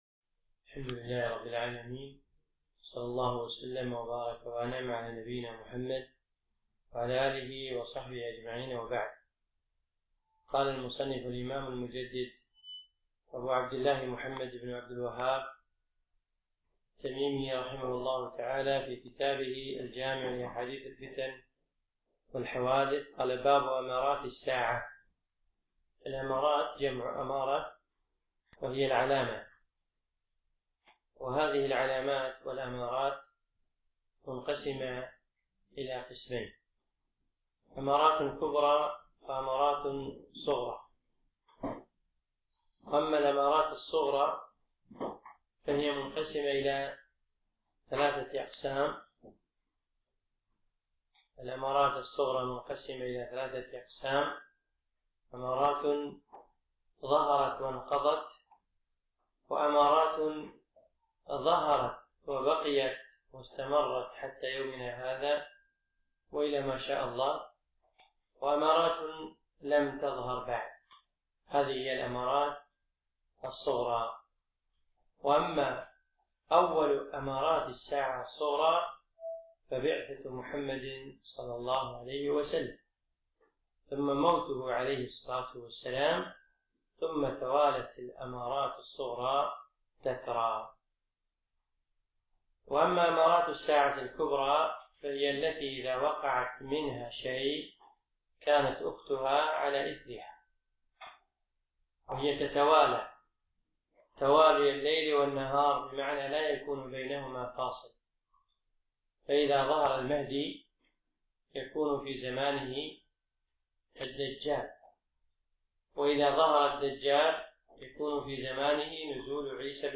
أقيمت الدورة في مركز القصر نساء مسائي
الدرس الثاني